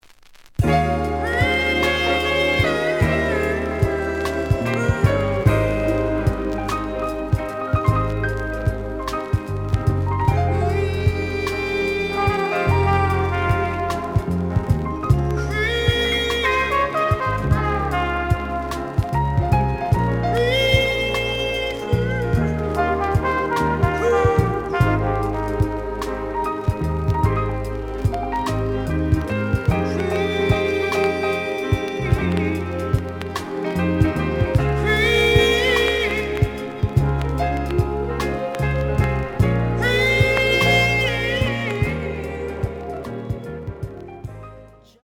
The audio sample is recorded from the actual item.
●Format: 7 inch
●Genre: Soul, 80's / 90's Soul